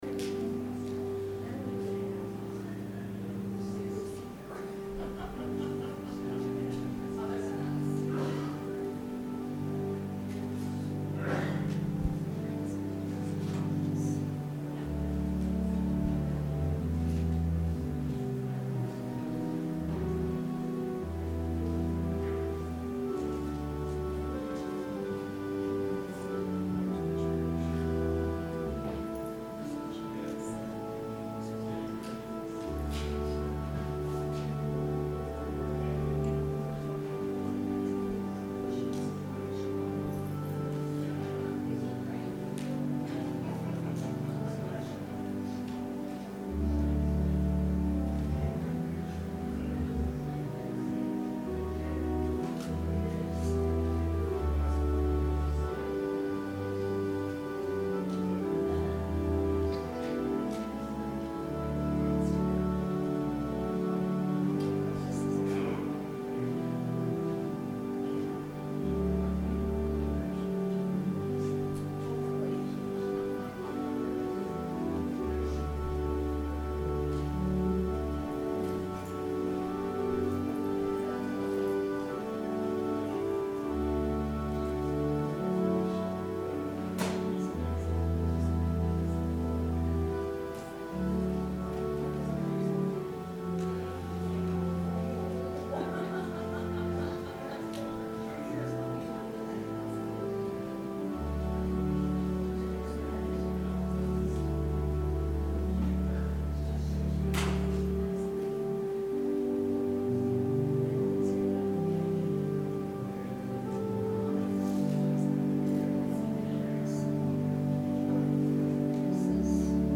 Sermon – November 25, 2018